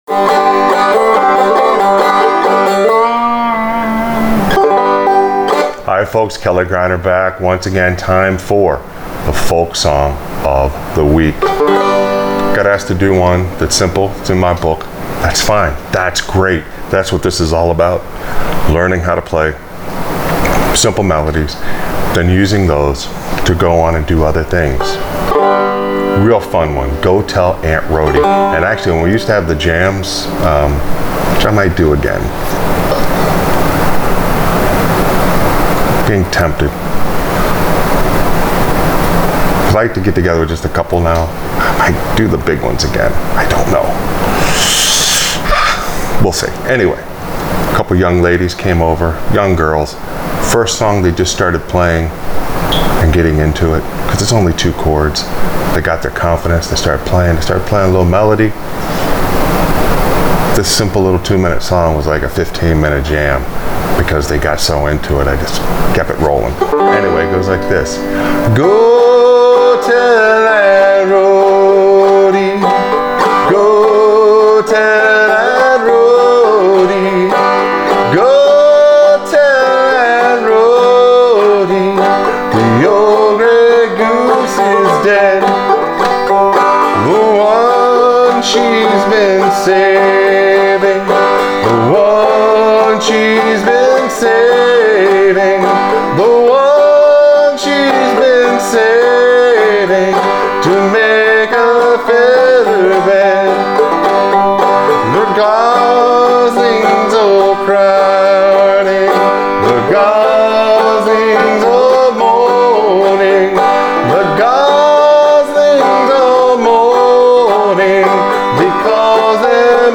Folk Song Of The Week – Frailing Banjo Lesson: Go Tell Aunt Rhody
Clawhammer BanjoFolk Song Of The WeekFrailing BanjoInstruction